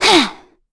Valance-Vox_Attack5_kr.wav